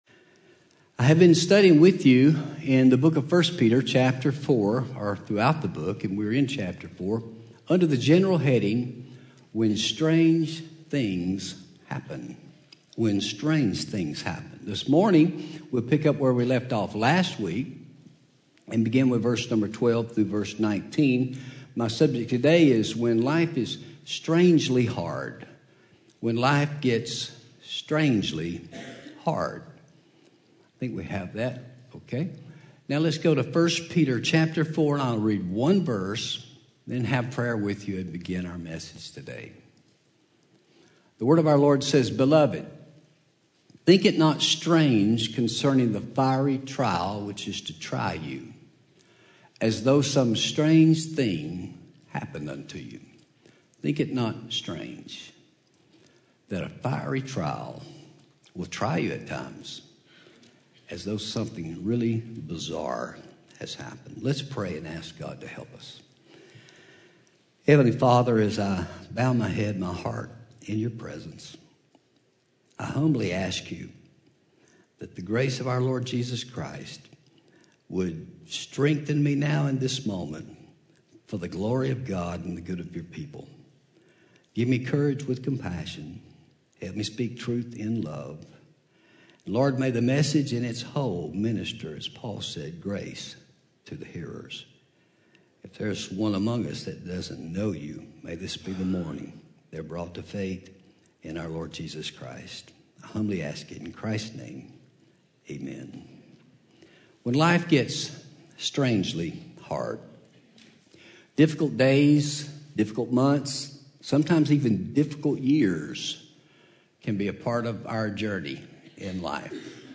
Home › Sermons › When Life Get’s Strangely Hard